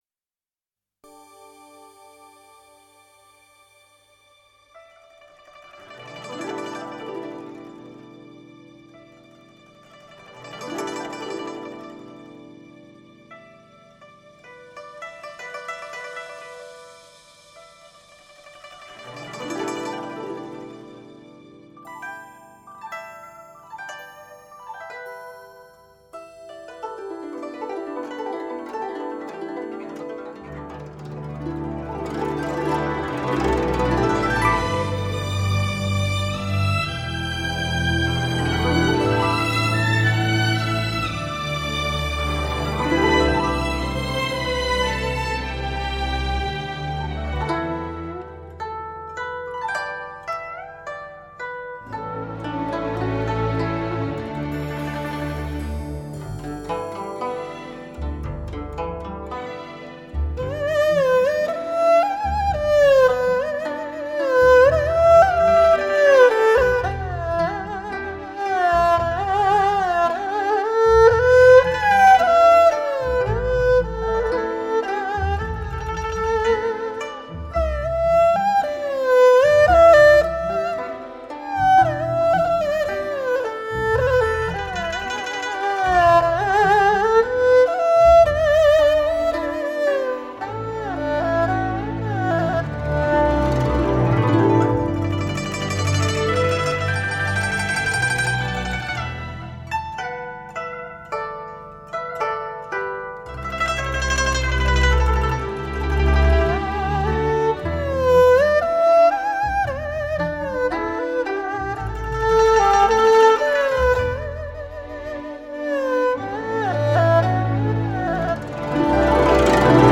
引用: 此张专辑送给深爱音乐的朋友们 充滿情感和靈感的聲音。